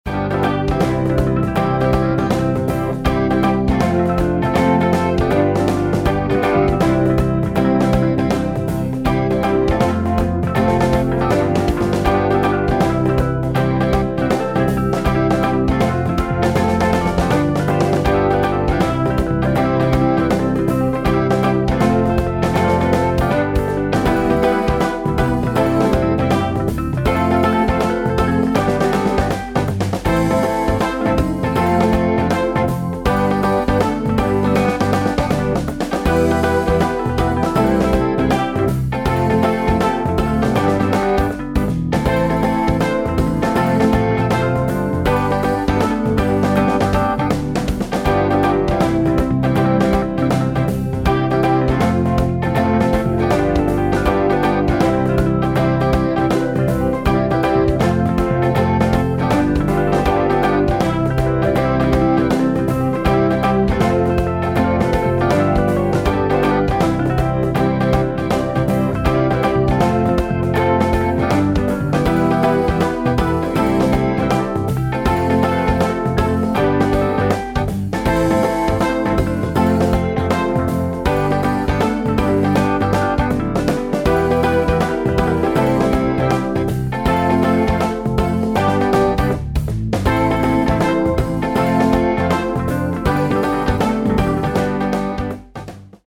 midi-demo 2 midi-demo 3